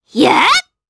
Pansirone-Vox_Attack1_jp.wav